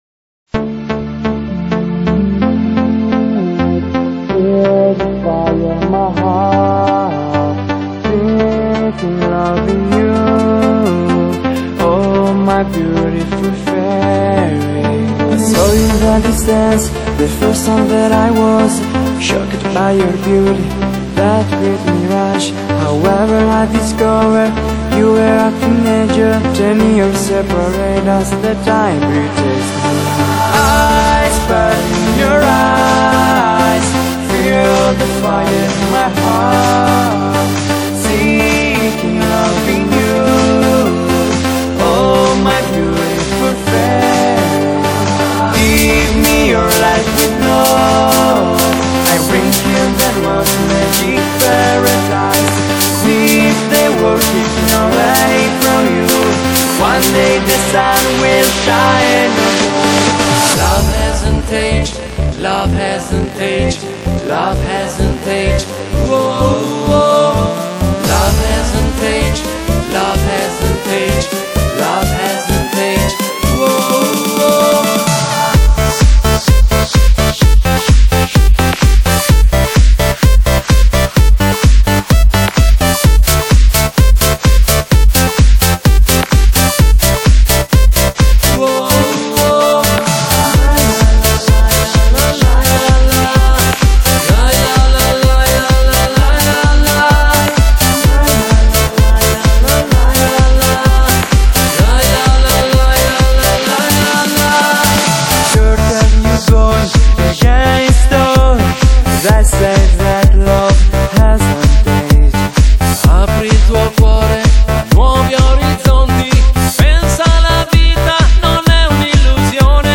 Genre: Dance, Pop |22Tracks |  (百度盤)=208M